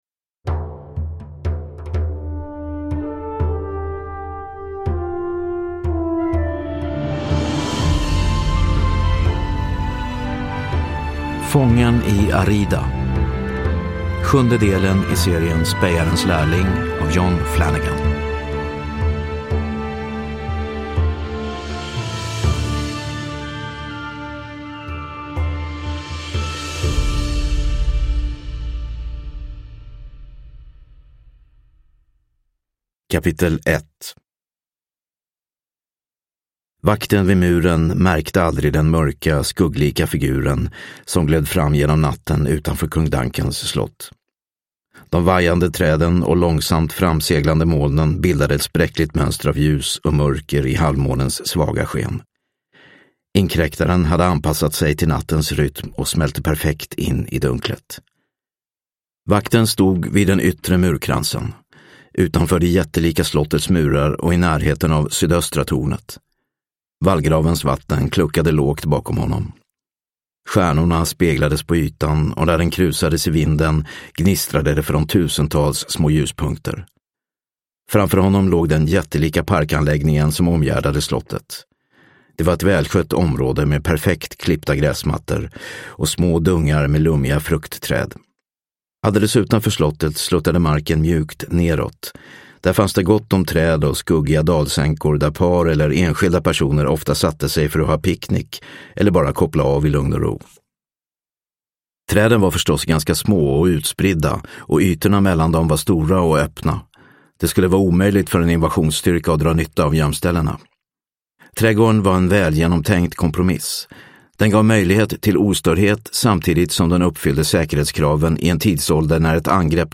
Fången i Arrida – Ljudbok – Laddas ner